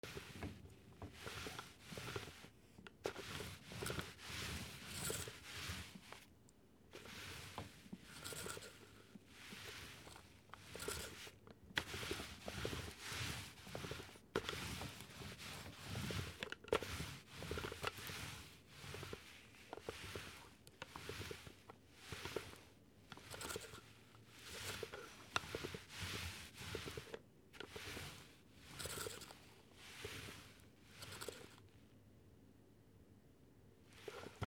コロコロ